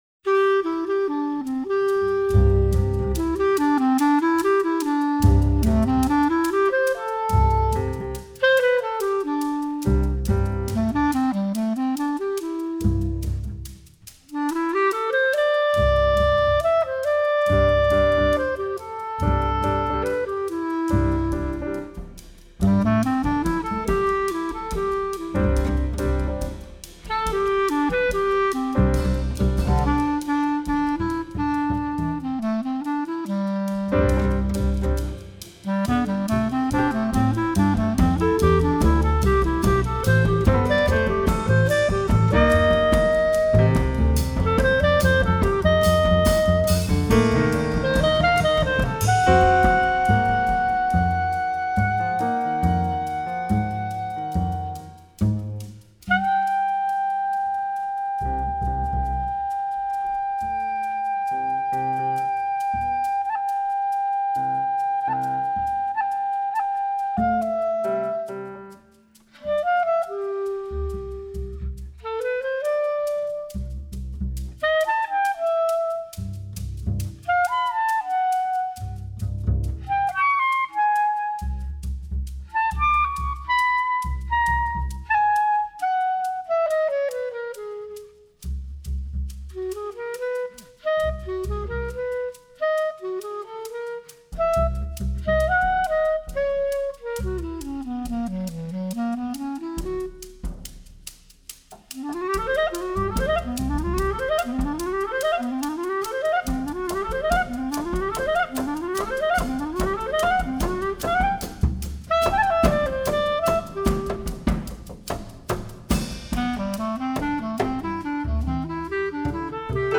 recorded live @ Constellation, Chicago, Il